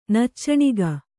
♪ naccaṇiga